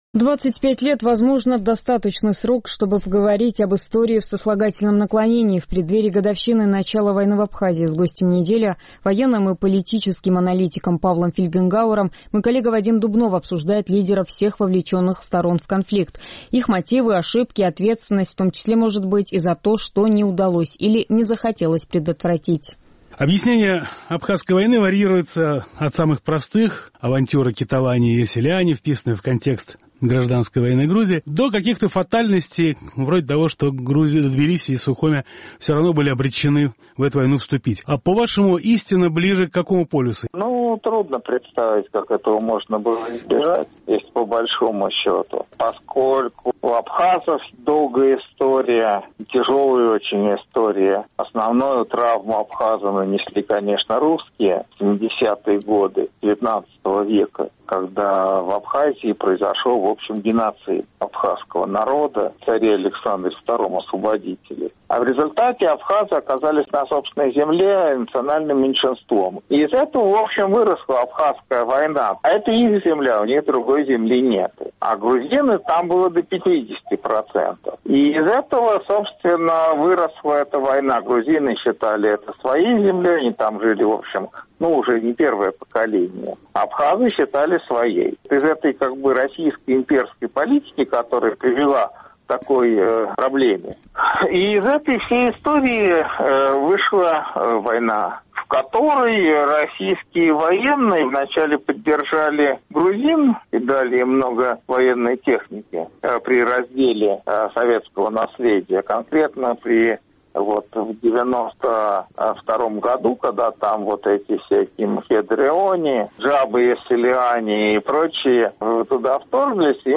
Гость недели – Павел Фельгенгауэр